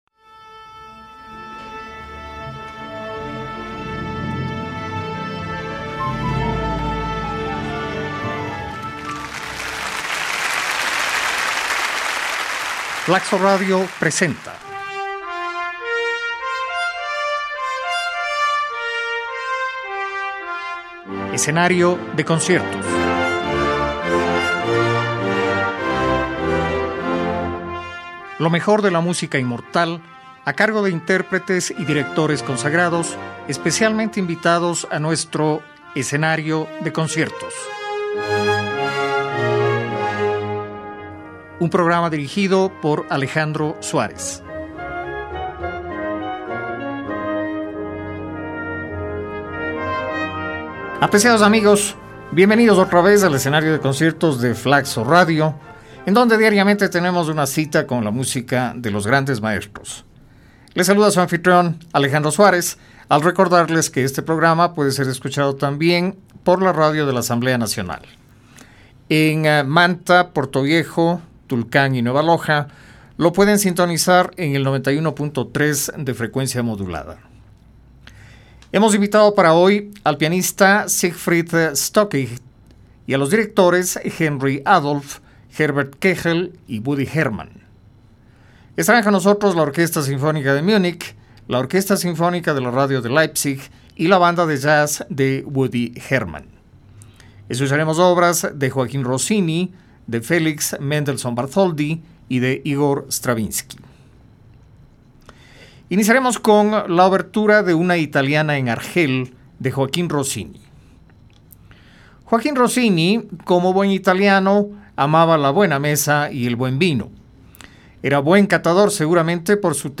Del gran compositor italiano, famoso también por su gusto por la comida y la bebida, escucharemos esta semana en el Escenario de Conciertos la Obertura de su ópera Una italiana en Argel. De otro gran italiano, Nicolo Paganini, famoso por los efectos teatrales que incluía en sus recitales, escucharemos el tercer concierto para violín.
En el día dedicado a las obras infrecuentes podremos apreciar una interesante versión en forma de concierto para piano de Cuadros de una exhibición, de Modest Mussorgsky.